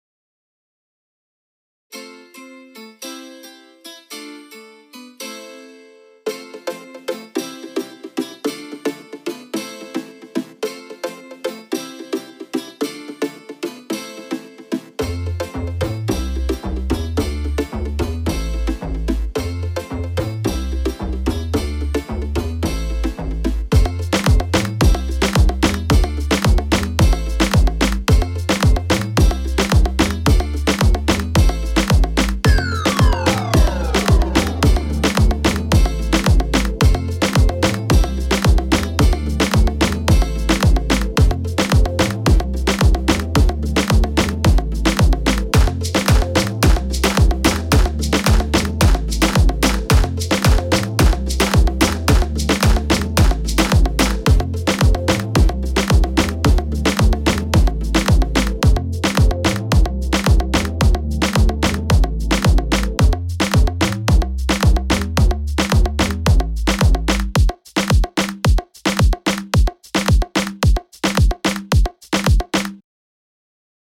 Home > Music > Pop > Electronic > Bright > Laid Back